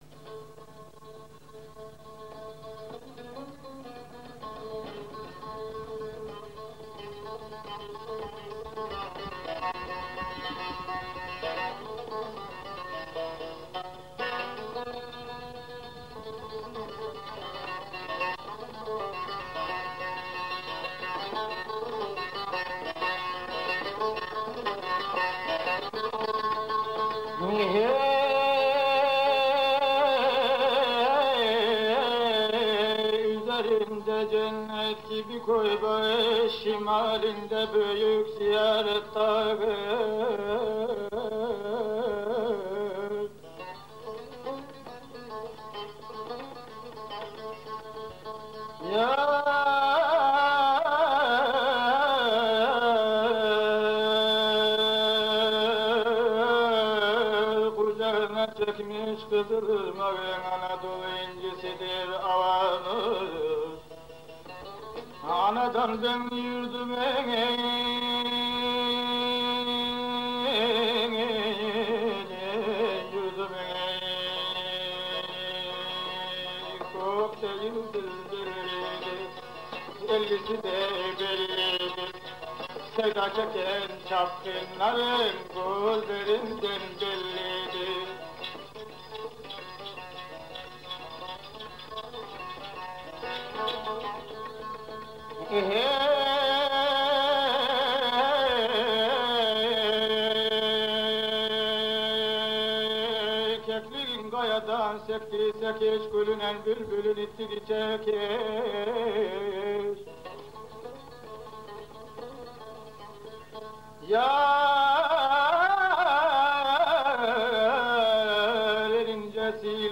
Taş plak